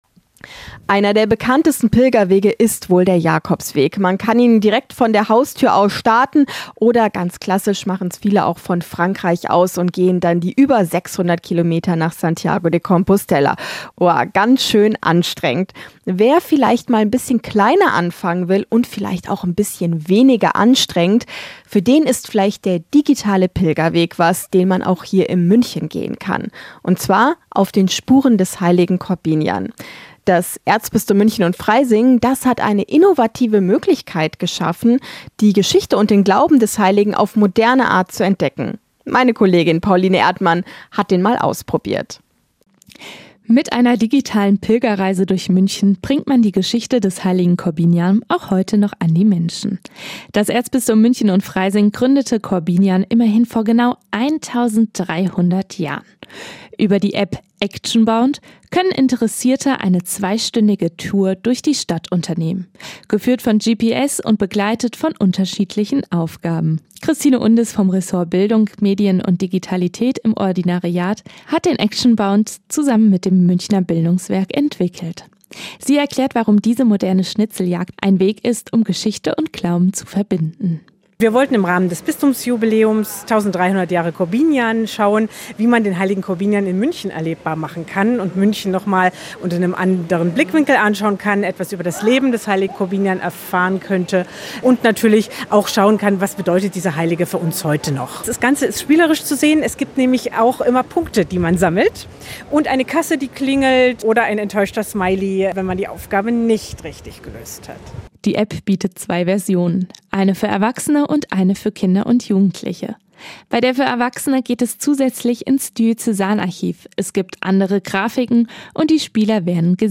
Radiobeitrag Münchener Kirchenradio